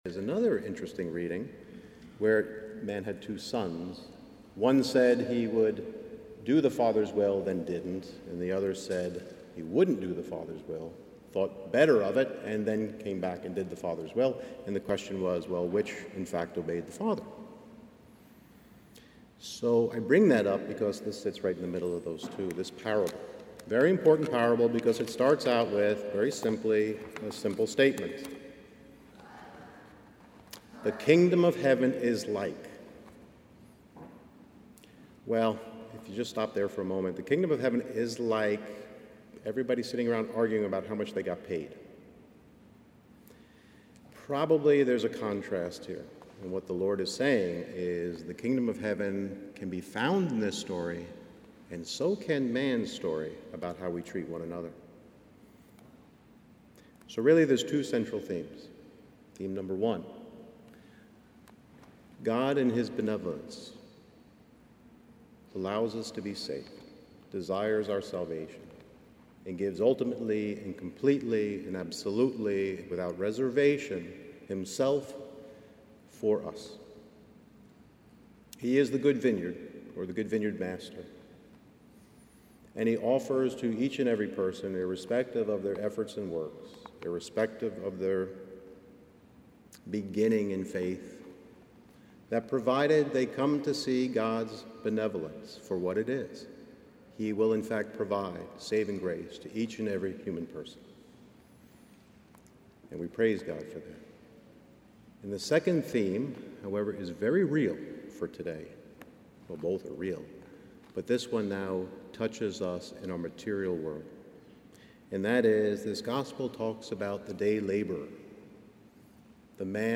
firsthomily.mp3